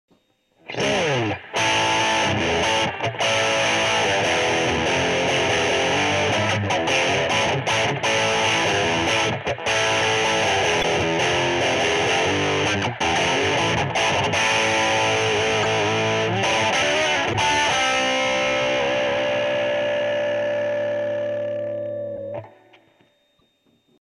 HP Celestion : Sonne comme les meilleurs marshall mais en transo ! Très typé JCM800, mais en plus domptable et plus pratiquable à faible volume ..
Reverb12 Satu3.mp3